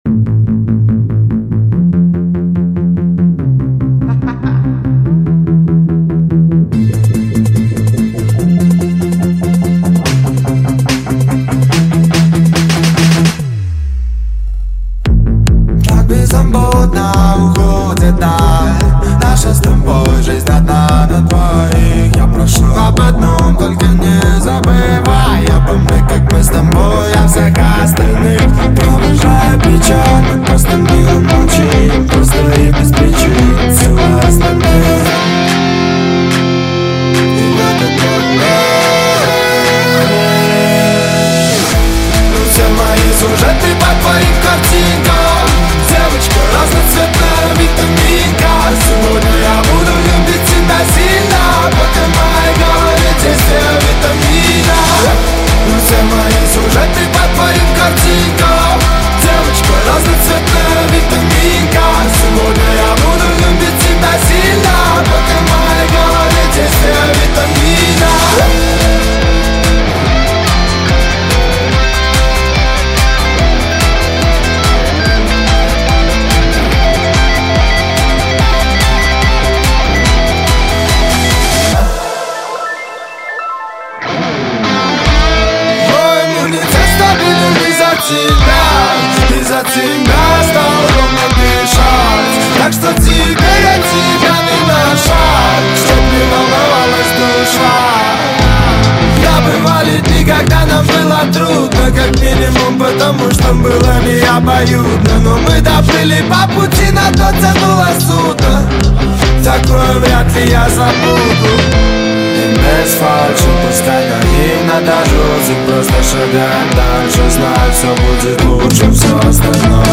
ТикТок Ремикс версия